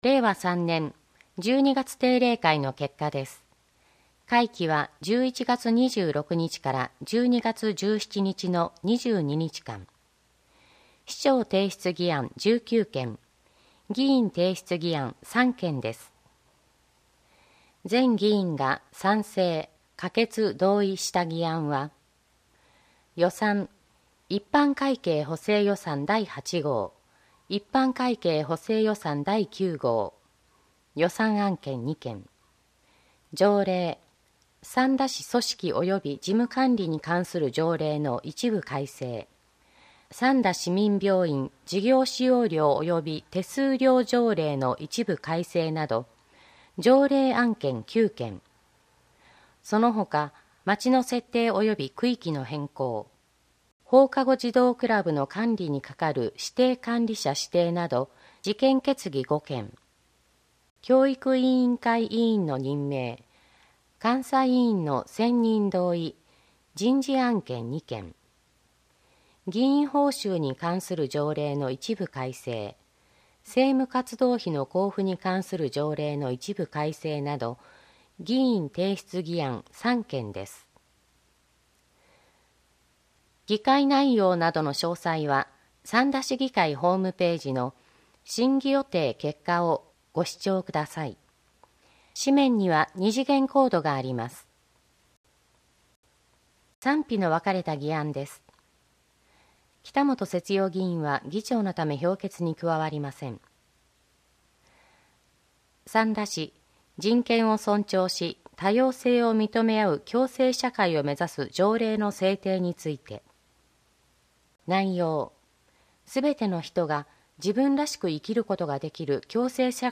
議会だより「つなぐ」掲載内容の音声データを、項目ごとに分けて配信しています。